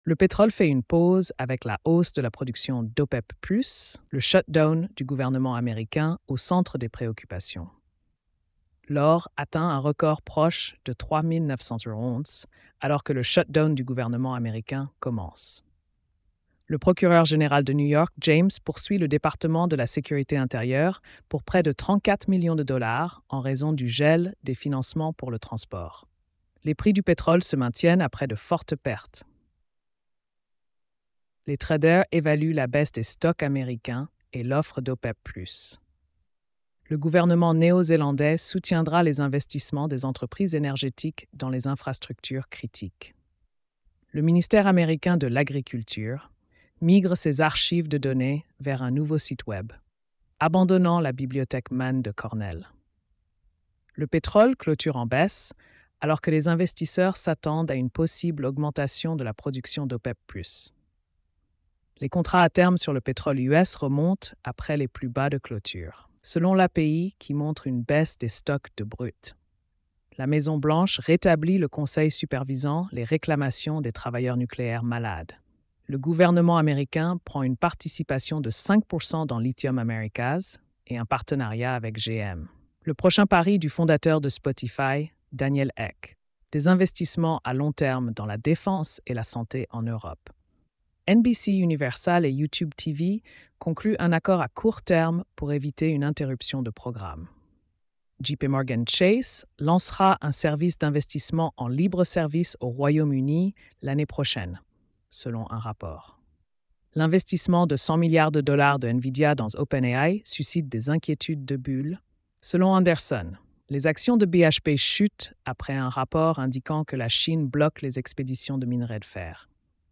🎧 Résumé économique et financier.